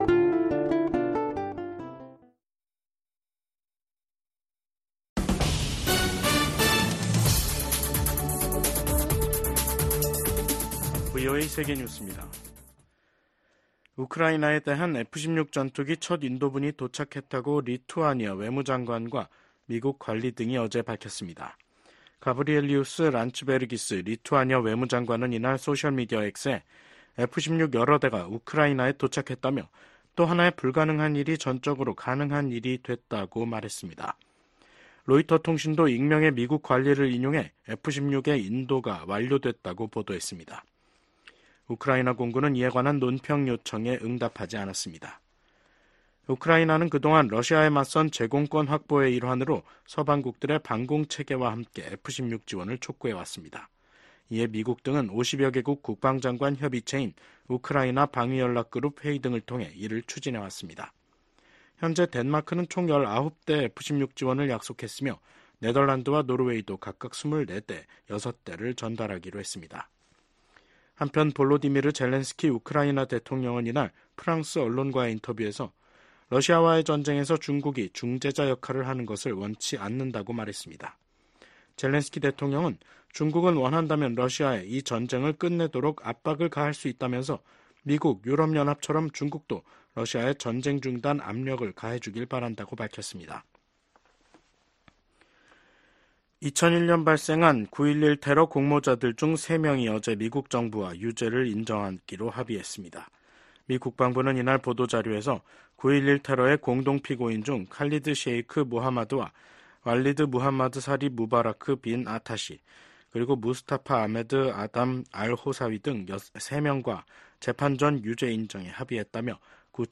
VOA 한국어 간판 뉴스 프로그램 '뉴스 투데이', 2024년 8월 1일 2부 방송입니다. 올 하반기 미한 연합훈련인 을지프리덤실드(UFS)가 오는 19일부터 실시됩니다.